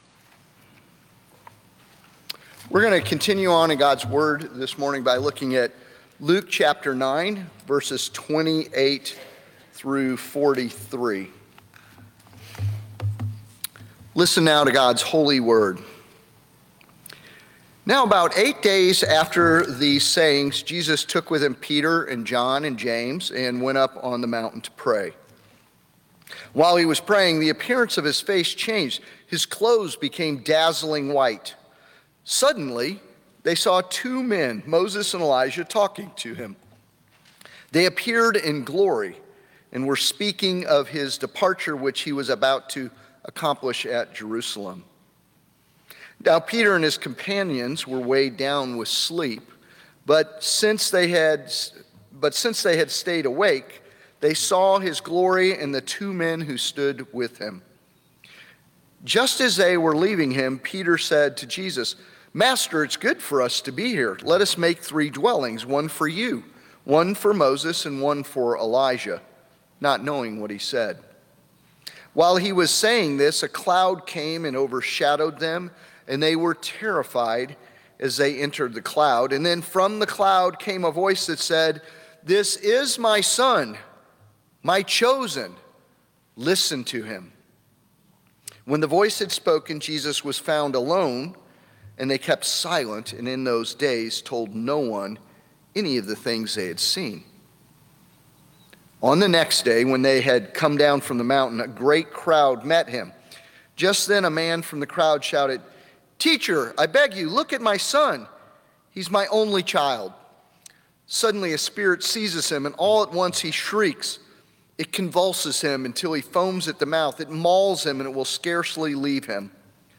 sermon-march-16-audio-1.m4a